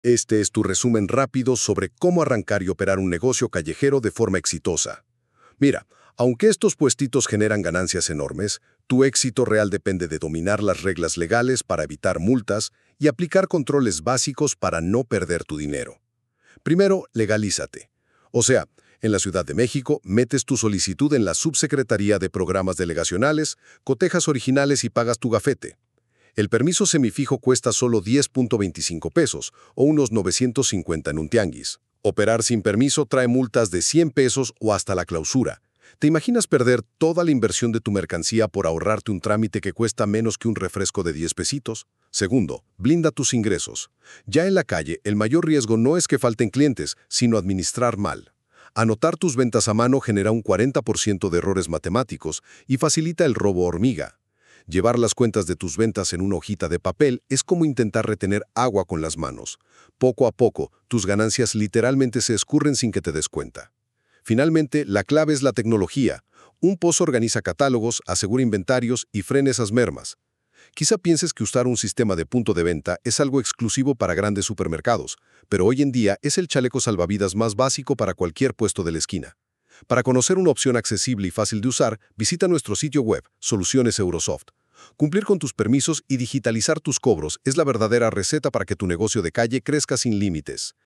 Escucha el resumen con todos los típs necesarios, narrado por expertos, en menos de 2 minutos.